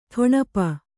♪ ṭhoṇapa